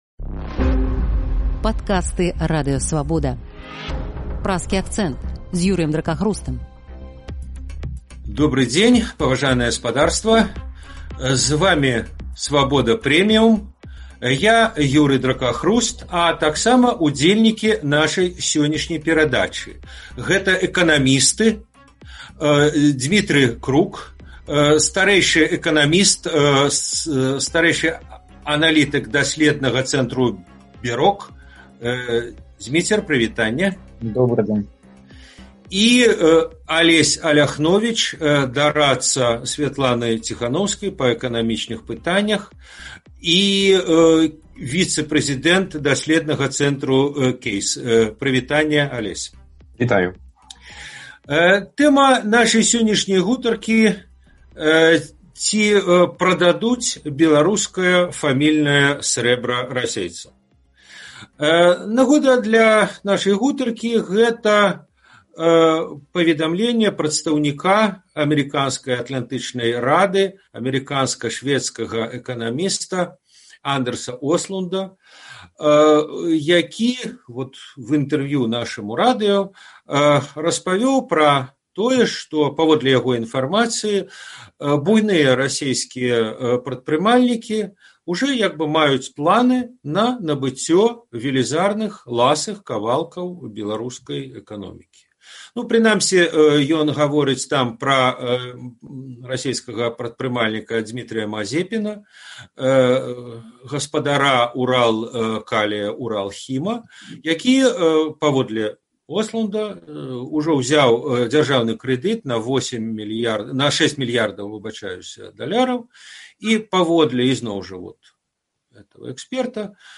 ў Праскім акцэнце разважаюць эканамісты